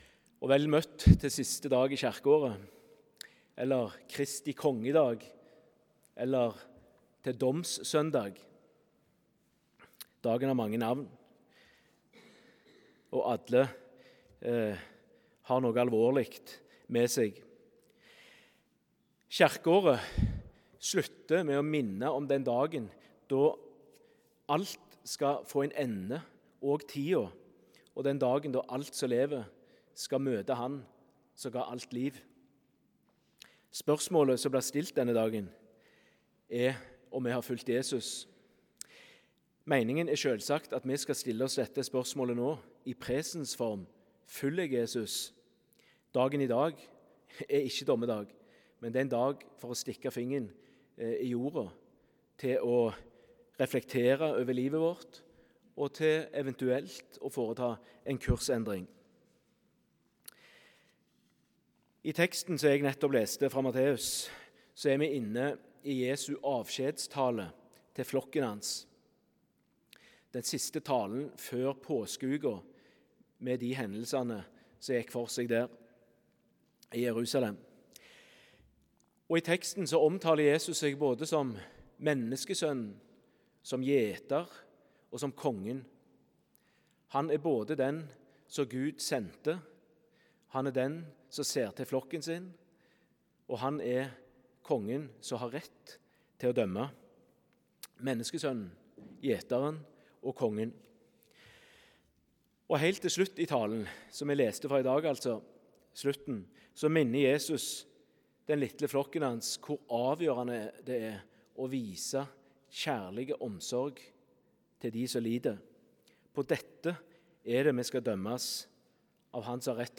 Tekstene Prekentekst: Matt 25,31–46 Lesetekst 1: Jes 57,14–16 Lesetekst 2: Åp 20,11–13 Utdrag fra talen (Hør hele talen HER ) Spørsmålet Spørsmålet som stilles denne dagen, er, kort fortalt, om vi har fulgt Jesus.